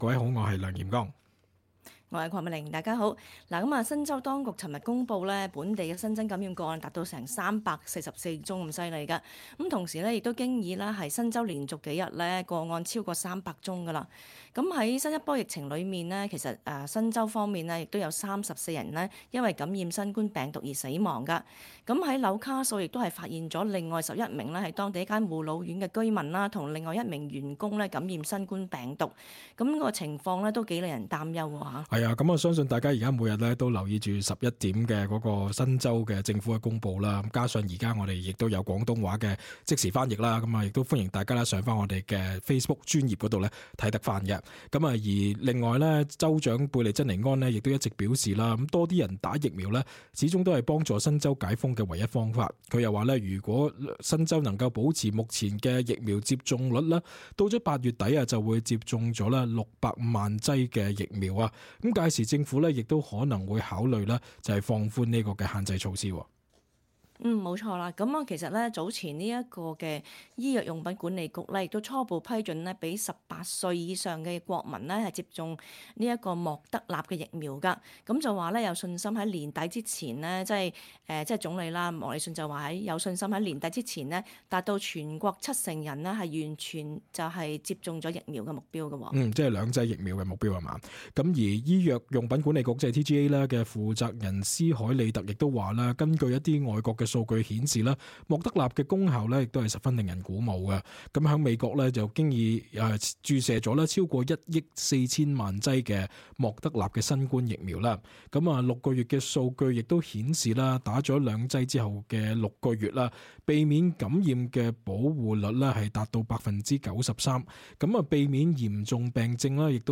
talkback_cantonese_-_aug_12_0.mp3